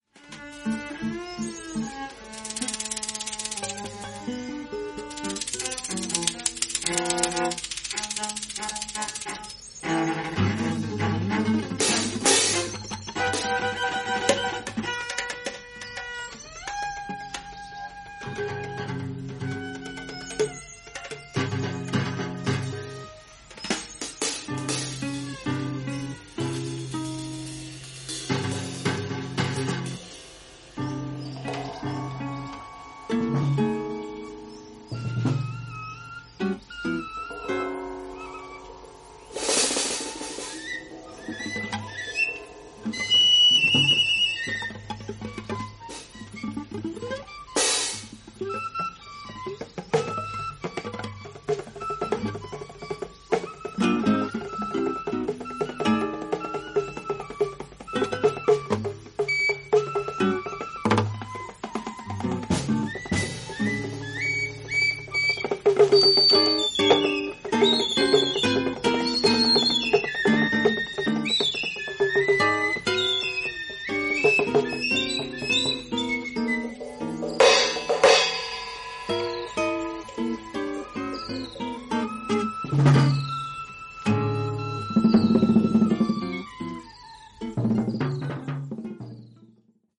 1969年9月、都内にあったテイチク会館スタジオにて、約6時間に及ぶ即興演奏をレコーディング。